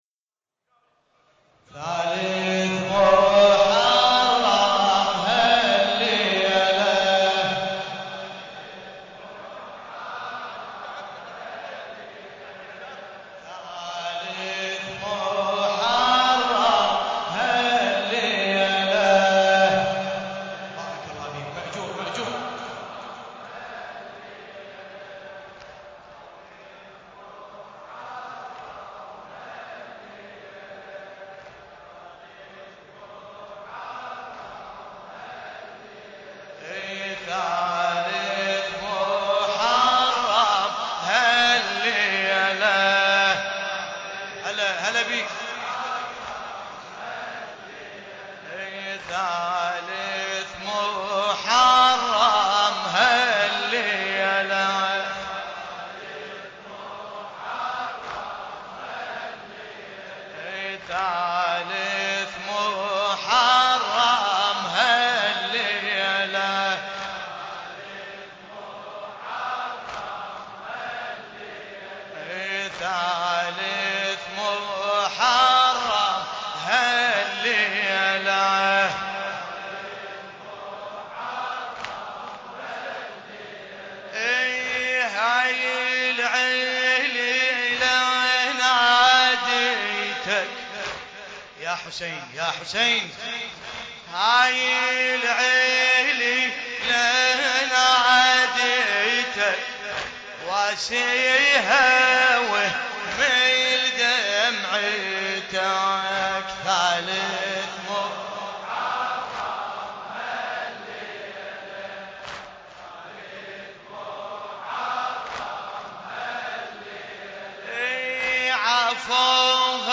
محرم الحرام